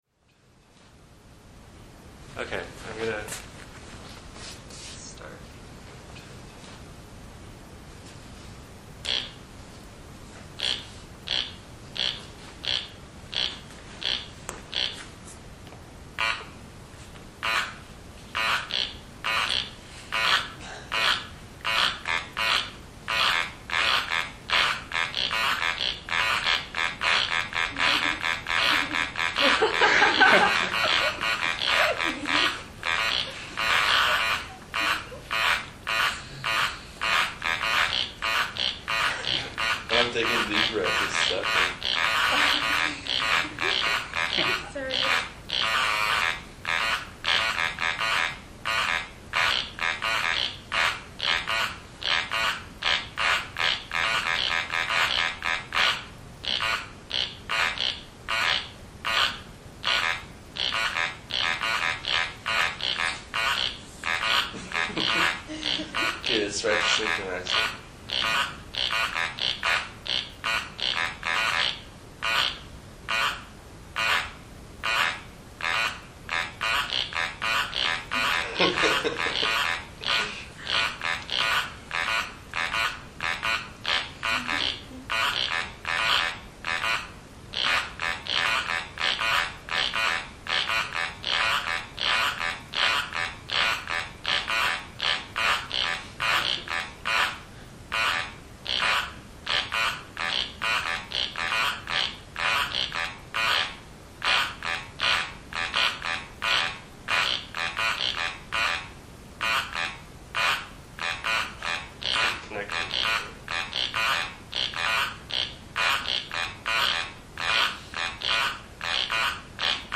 Each performer is connected to a device that monitors his heartbeat and turns each beat into an audible sound. Each device is set to emit a different tone. The performers are instructed to try to synchronize their heartbeats.
version for 3 performers (download mp3) (beats) is a musical composition.